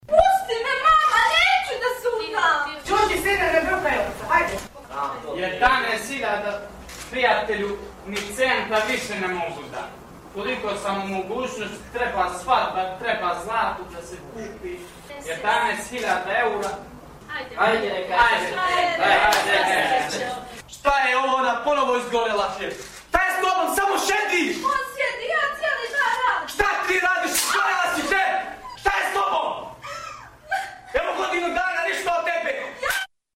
Aktivisti romskih nevladinih organizacija su prije nekoliko mjeseci u crnogorskom parlamentu prikazali predstavu pod nazivom „Nametnuta sudbina“, čiji je cilj bio da i političkim činiocima i ukupnoj javnosti skrenu pažnju na ovaj problem.
Iz predstave "Nametnuta sudbina"